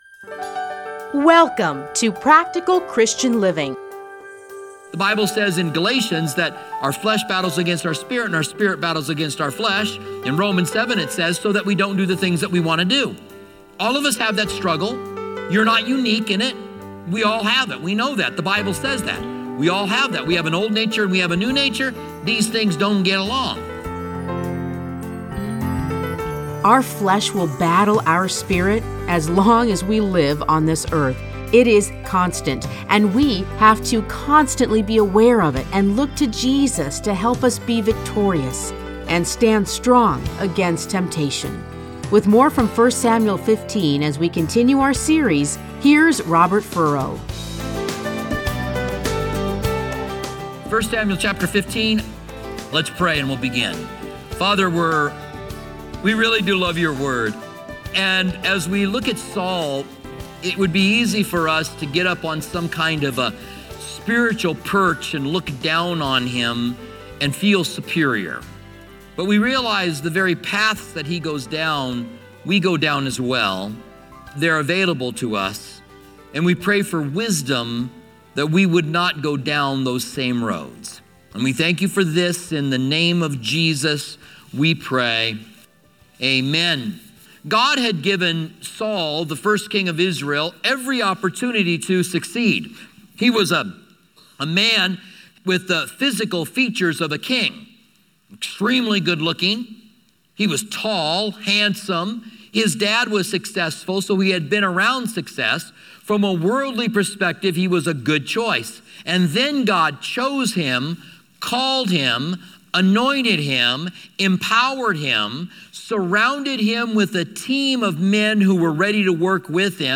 Listen to a teaching from 1 Samuel 14:1-35.